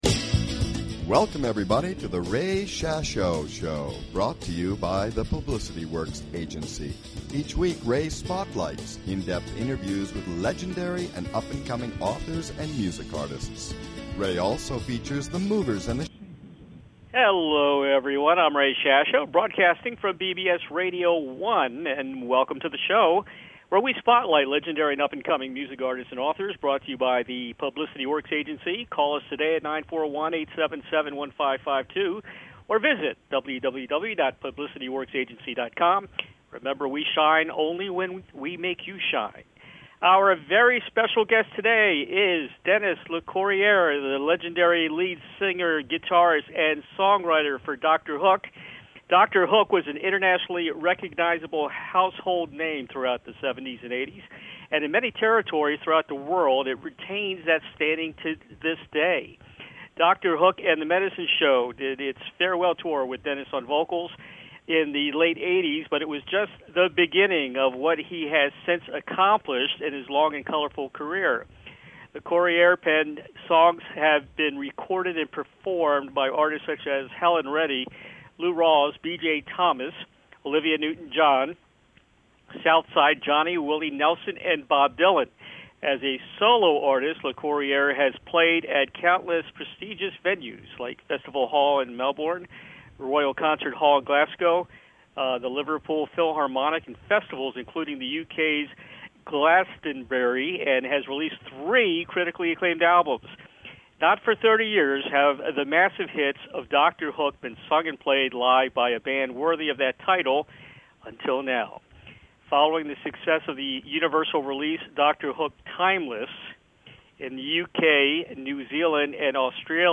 Talk Show Episode
Guest, Dennis Locorriere